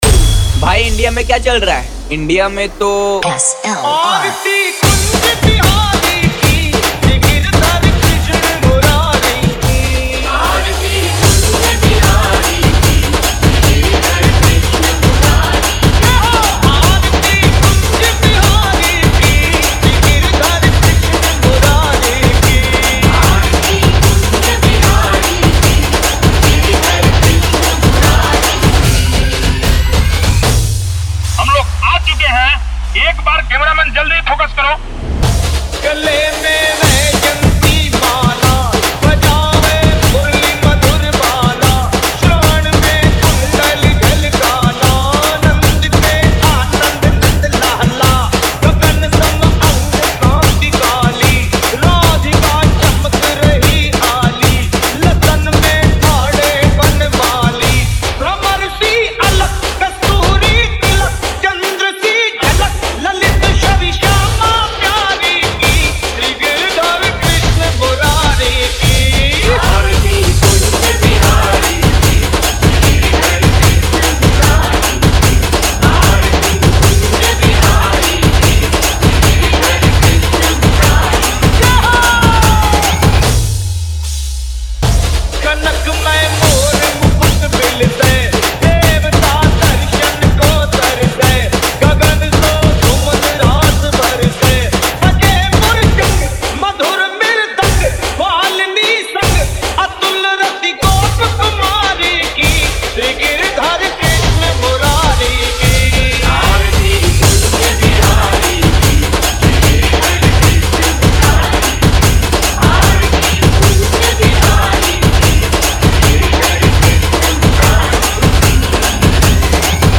- bhakti dj song